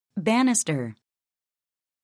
[bǽnəstər]